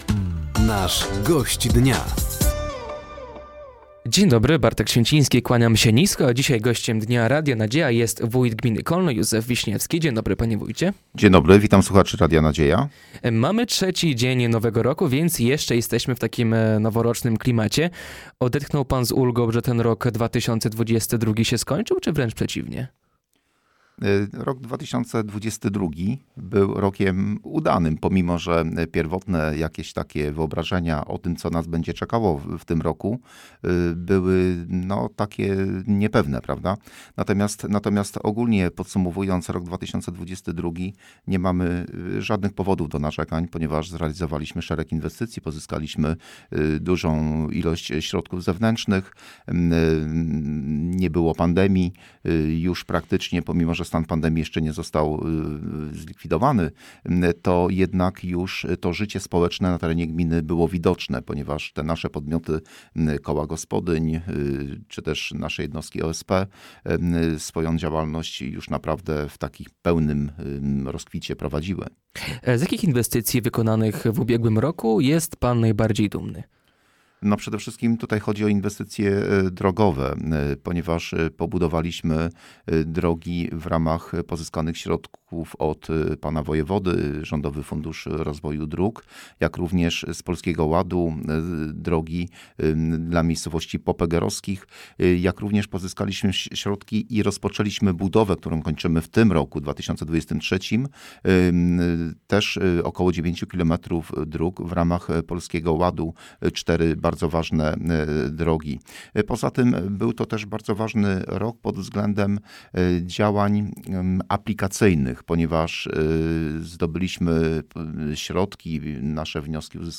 Gościem Dnia Radia Nadzieja był wójt gminy Kolno, Józef Wiśniewski. Wójt podsumował ubiegły rok, powiedział o najważniejszych zrealizowanych inwestycjach oraz o planach i zadaniach na 2023.